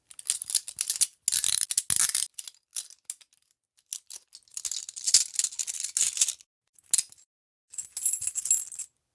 handcuffon.ogg